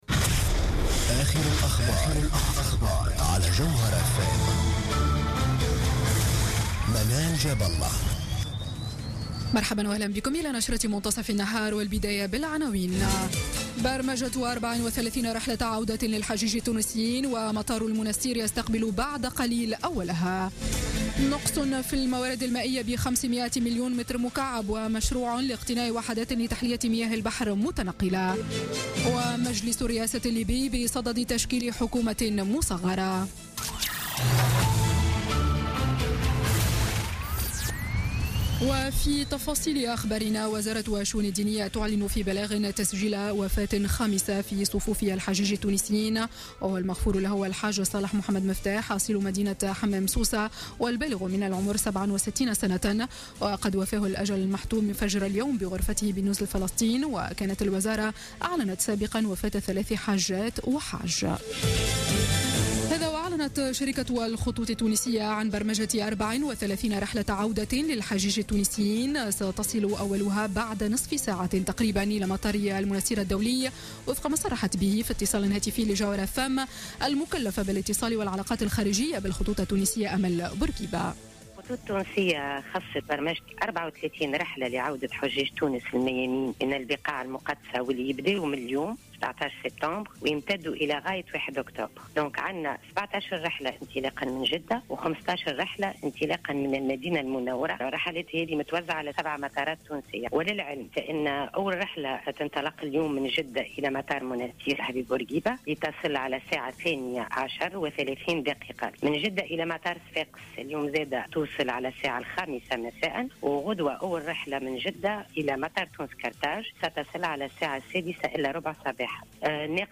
نشرة أخبار منتصف النهار ليوم السبت 17 سبتمبر 2016